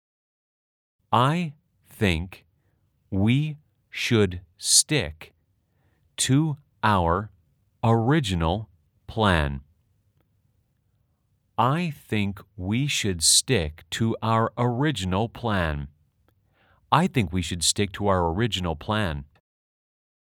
/ 아이 띠잉크 위 슈욷 /
/ 스띡투 아워 / 어뤼지널 / 플래앤 /
원어민 음성파일을 따로 저장하지 않아도 스마트 폰으로 바로 들을 수 있습니다.